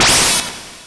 se_powerup.wav